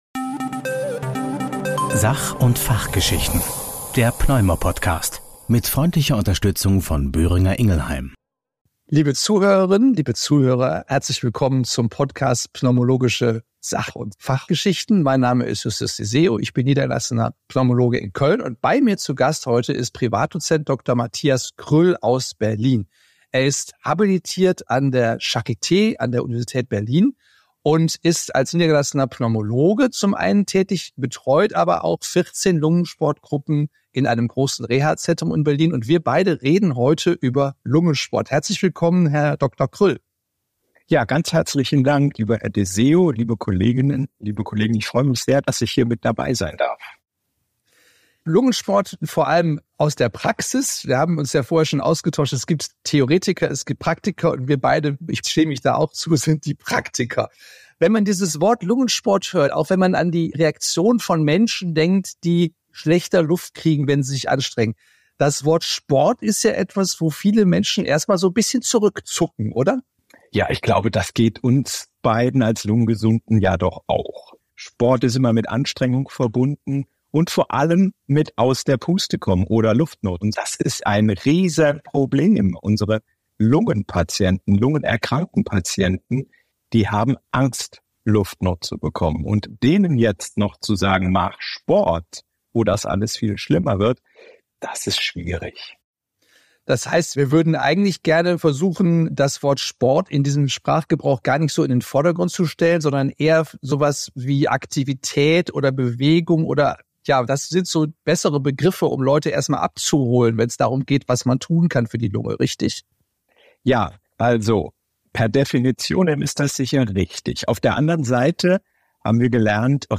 Als niedergelassene Pneumologen sind die beiden Gesprächspartner oftmals mittendrin, wenn es darum geht, an Atemnot leidende Patient:innen zum Lungensport zu motivieren.